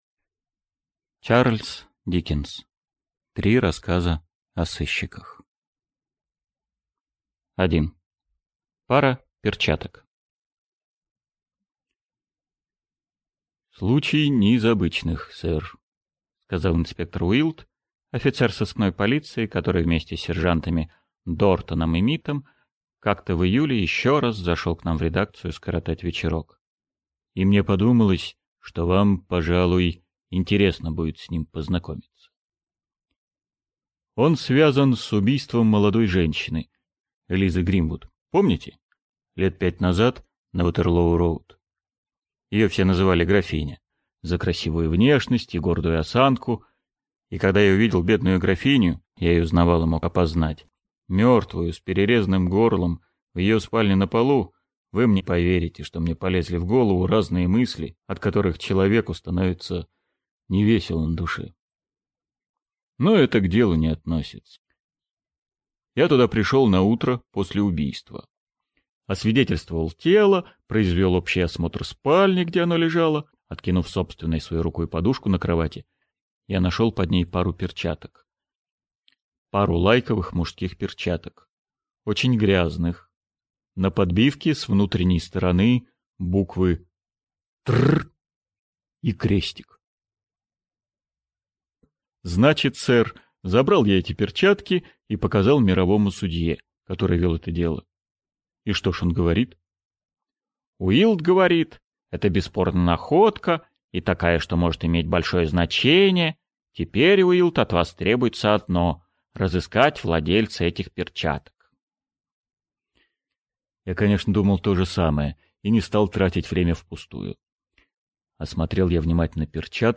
Аудиокнига Три рассказа о сыщиках | Библиотека аудиокниг
Aудиокнига Три рассказа о сыщиках Автор Чарльз Диккенс Читает аудиокнигу Евгений Лебедев.